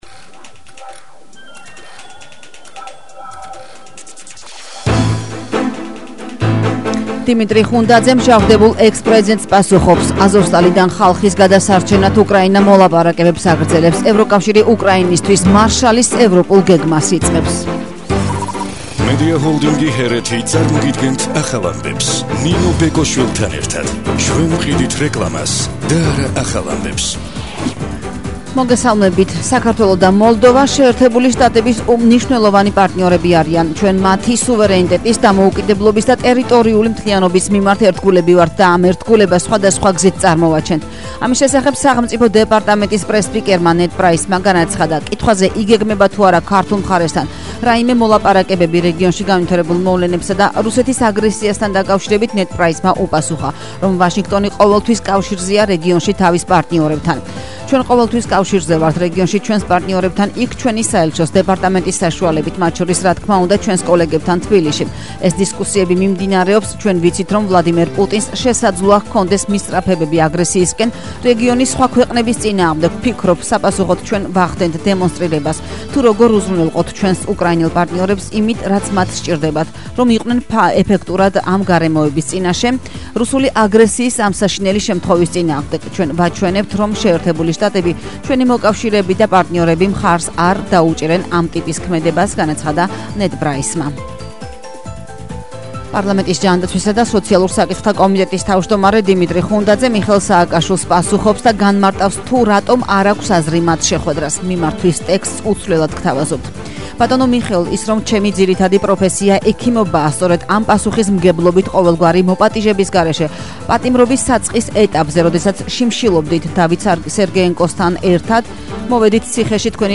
ახალი ამბები 12:00 საათზე – 05/05/22 - HeretiFM